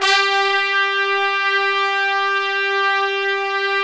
Index of /90_sSampleCDs/Roland LCDP06 Brass Sections/BRS_Tpts mp)f/BRS_Tps Velo-Xfd